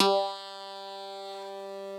genesis_bass_043.wav